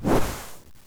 attack3.wav